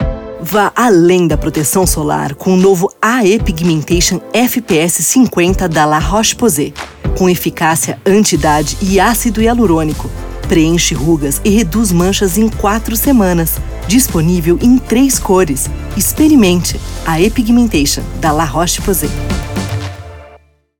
Feminino
LOREAL (madura, atitude)
Voz Padrão - Grave 00:20